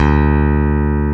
Index of /90_sSampleCDs/Roland LCDP02 Guitar and Bass/BS _E.Bass 2/BS _Rock Bass
BS  ROCKBSD3.wav